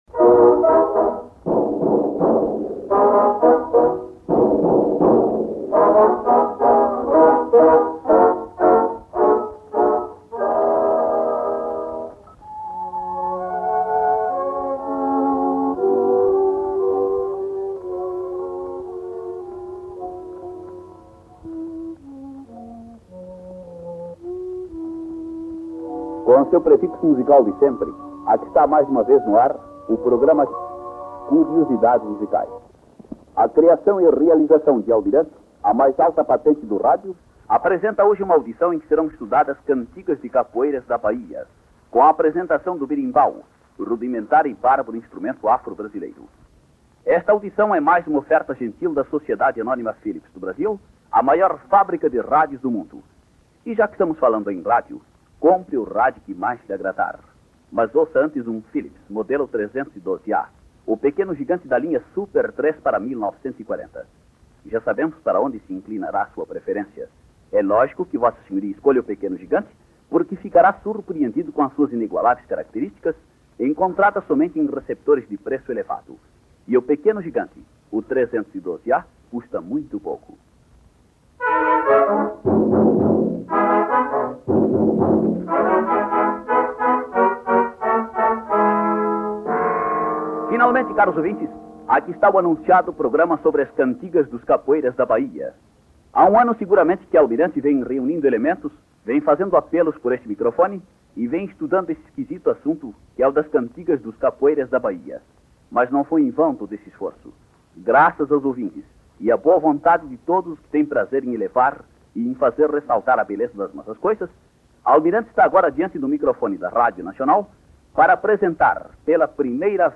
02. Cantigas de Capoeiras da Bahia
01._Cantigas_de_Capoeiras_da_Bahia.mp3